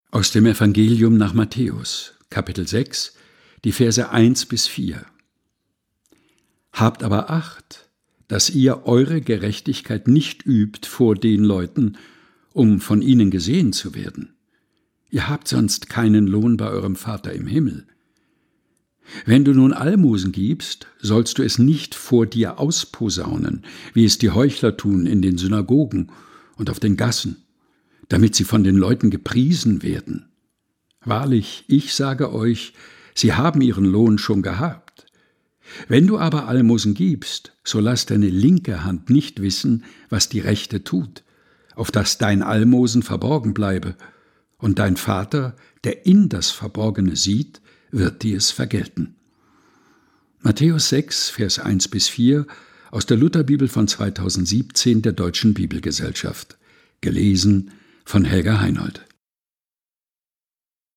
Predigttext zum Nikolaustag 2024.